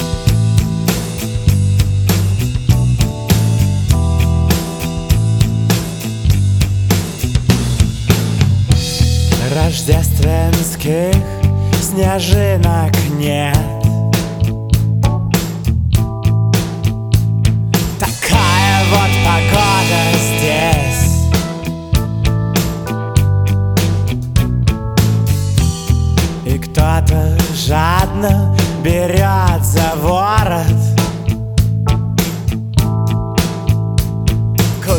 Rock Alternative